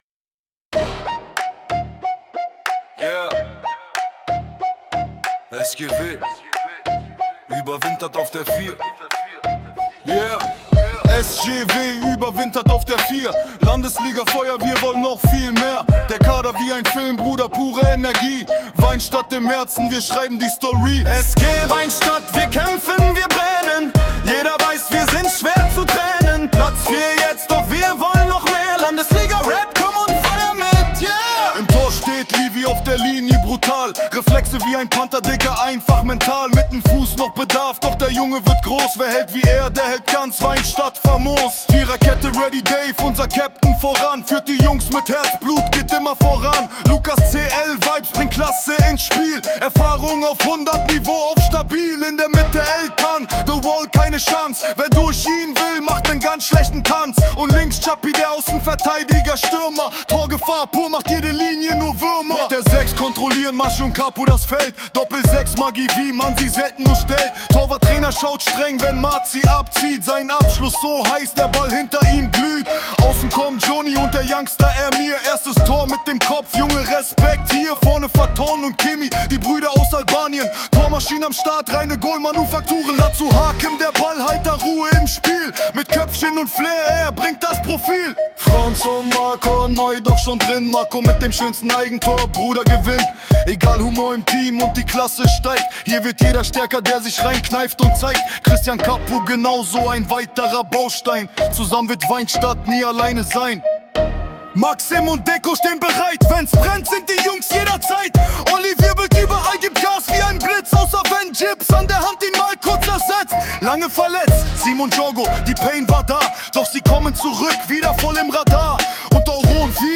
Nach 6 Siegen in Folge haben die Aktiven in der Landesliga 1 nun einen beachtlichen 4. Platz zu feiern. Grund genug, dieses in einen geilen Rapp zu fassen.